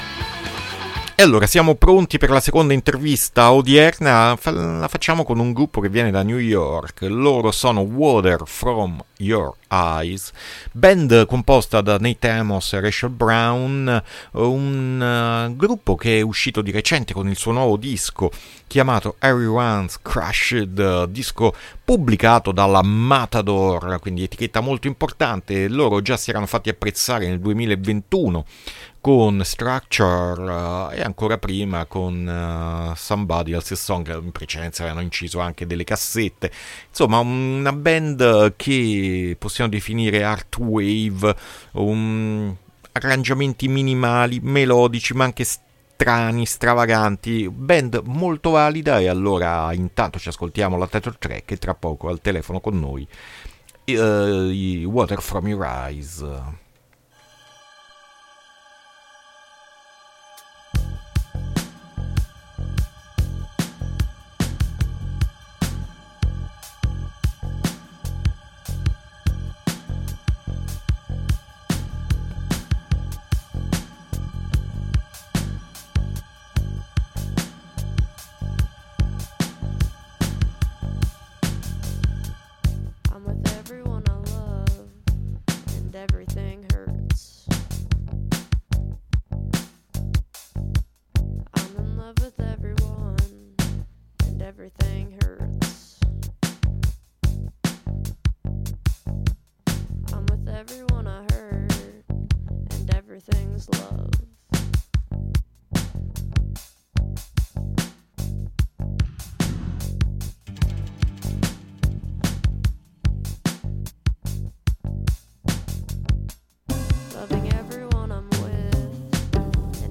INTERVISTA WATER FROM YOUR EYES A MERCOLEDI' MORNING 7-6-2023